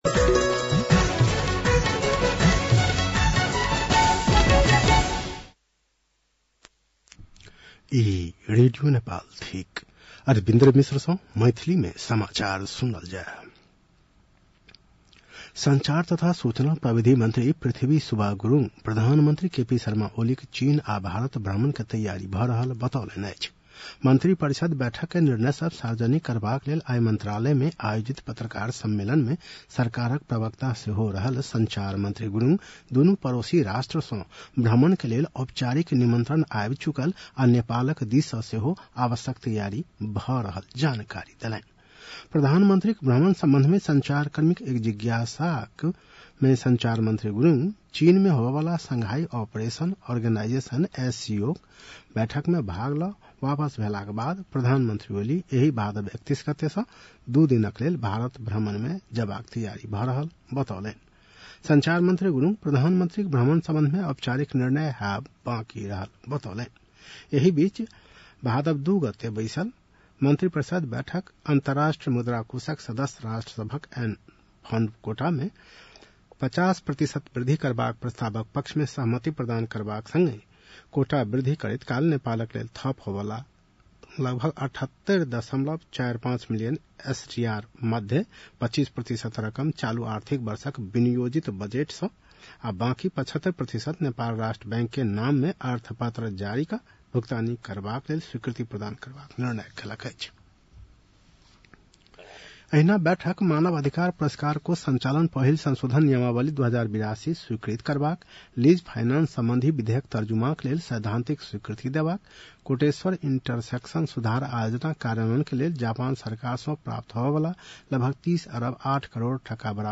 मैथिली भाषामा समाचार : ४ भदौ , २०८२